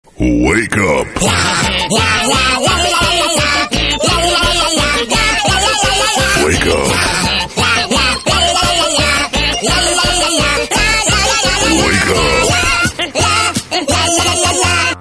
tone-to-wake-up_24753.mp3